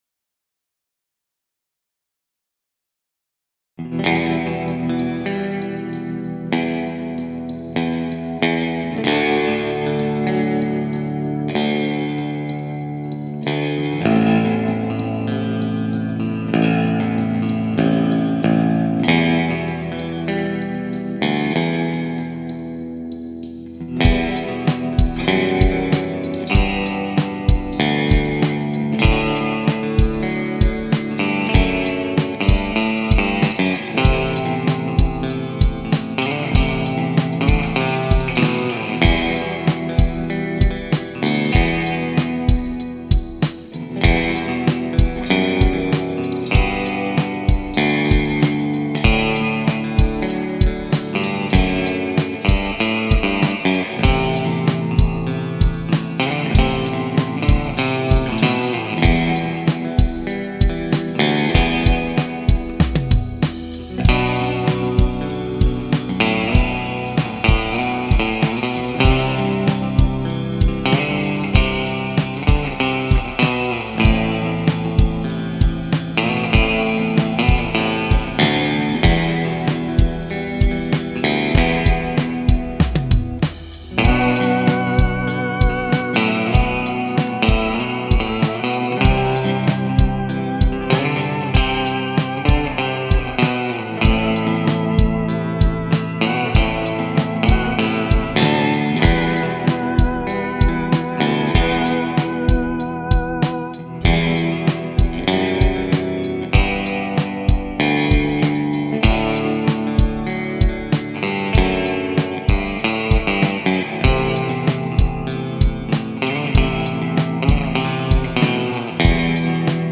And here is the first Baritone demo tune
The left channel has the Baritone doing some rhythm
The right channel is my Squier Jazzmaster doing chords
Right down the center is the Baritone doing lead